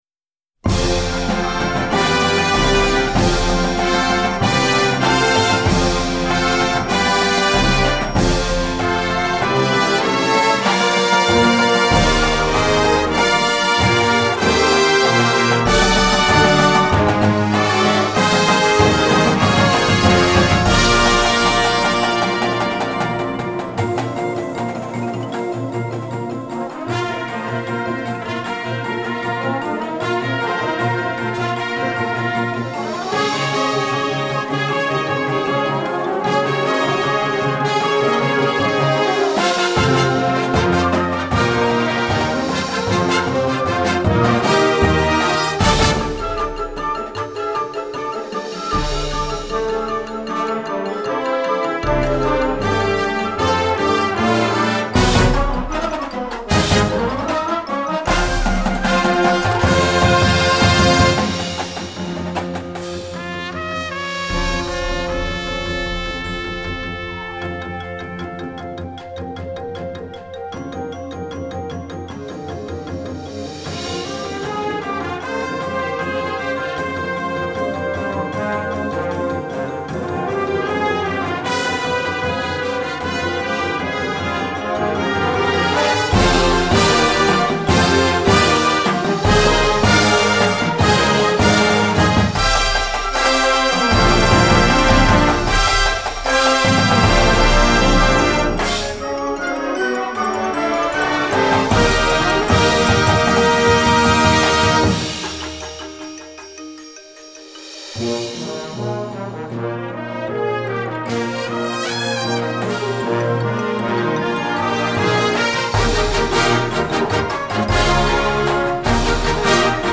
modern Marching Band Show scored for smaller bands